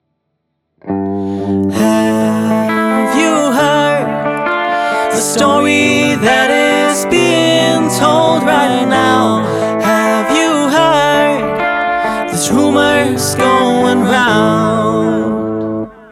Hach. Mal eben als Schnellschuss, gefällt mir aber an sich irgendwie (muss natürlich noch deutlich mehr metern, aber you get the idea...).
Hat nen Beatles Touch, weiter machen, in den Gitarren gehen diese Vocals besser auf, die Kaschieren den schrägen Gesang das harmoniert besser.